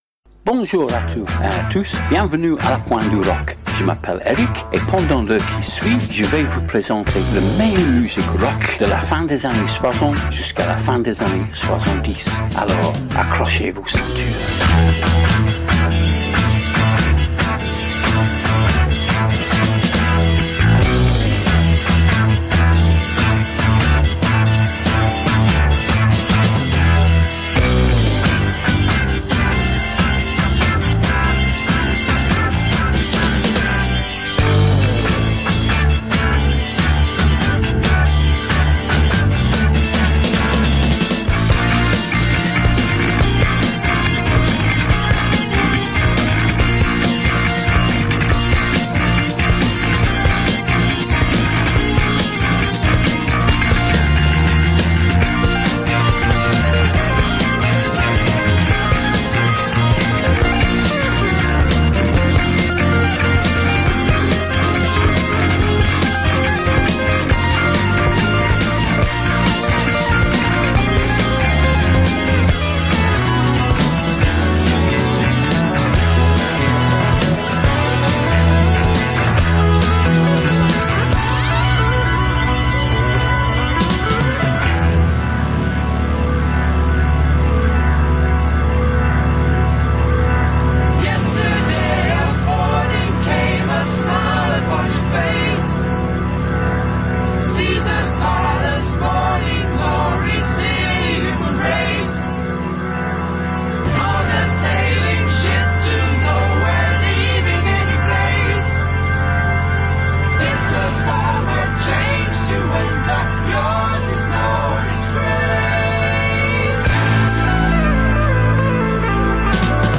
Du bon rock anglophone des années 60/70